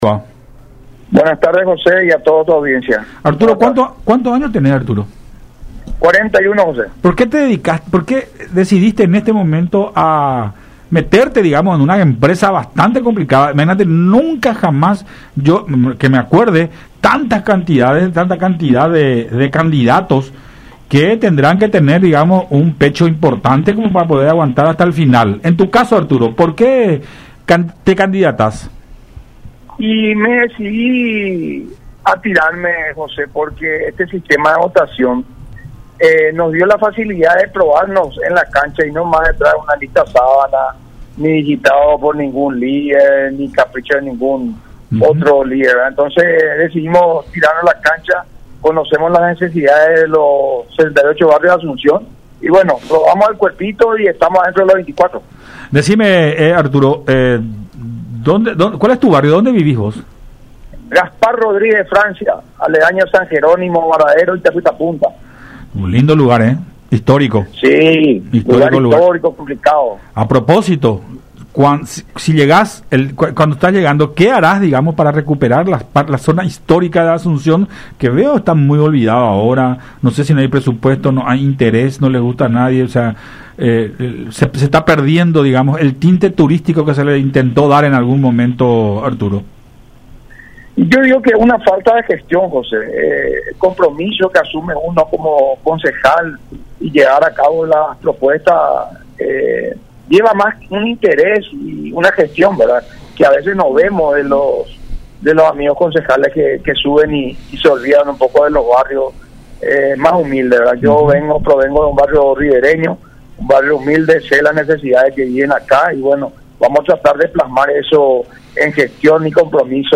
en conversación con Buenas Tardes La Unión